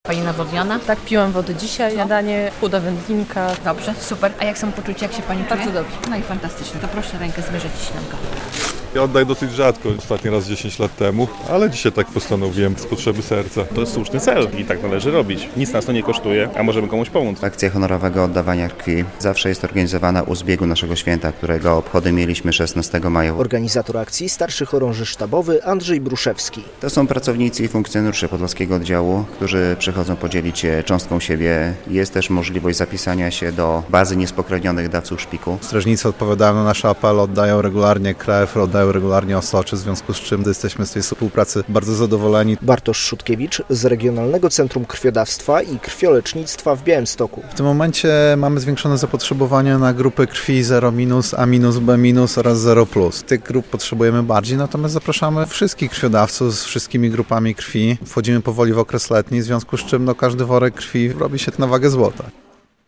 Zbiórka krwi w Straży Granicznej - relacja